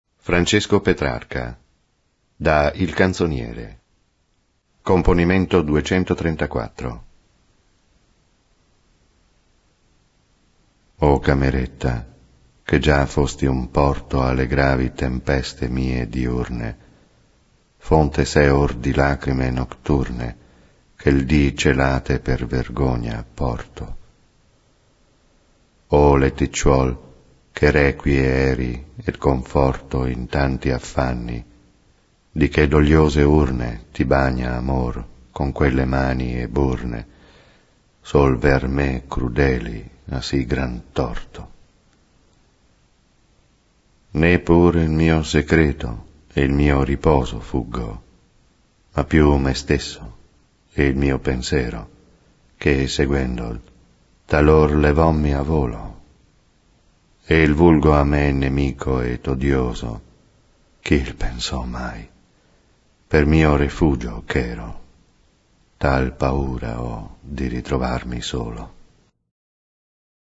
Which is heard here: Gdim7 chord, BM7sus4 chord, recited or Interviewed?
recited